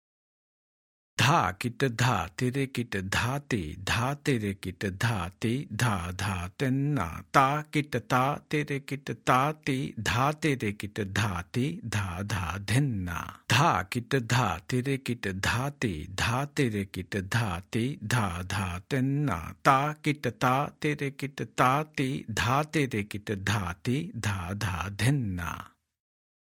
Spoken – Version 1